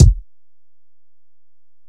Kick (8).wav